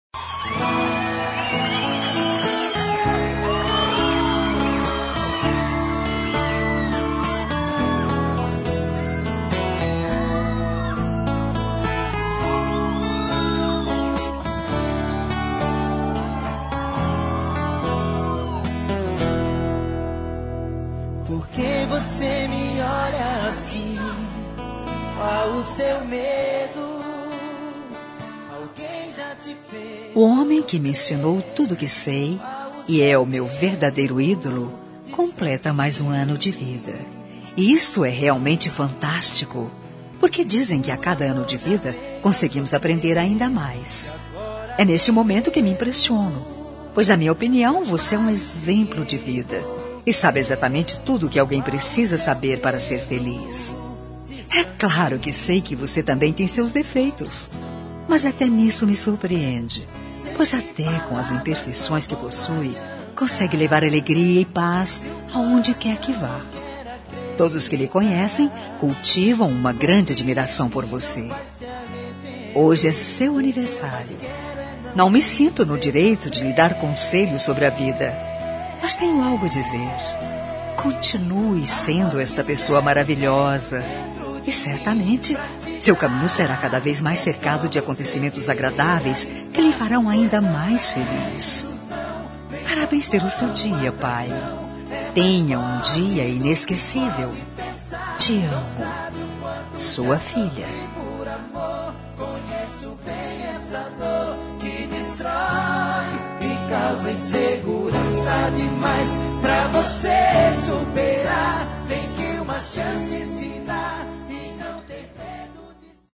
Telemensagem de Aniversário de Pai – Voz Feminina – Cód: 1469